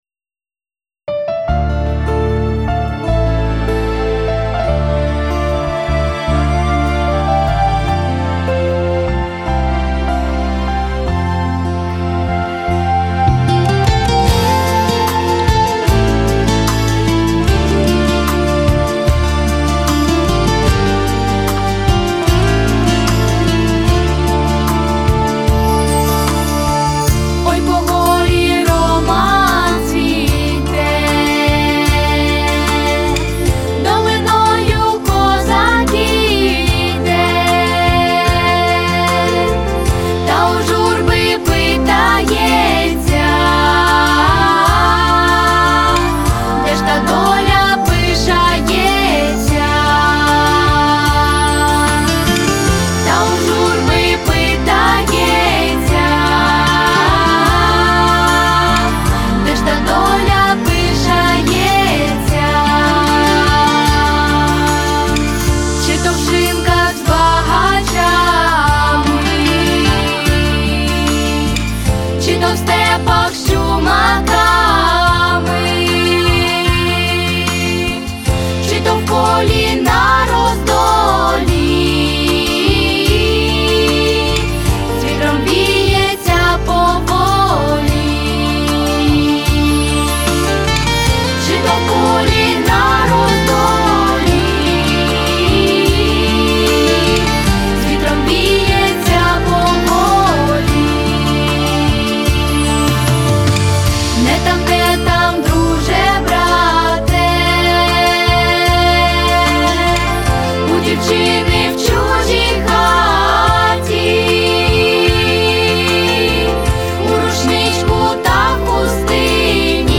дитячий ансамбль
Тональність – ре мінор, фактура – три голоси.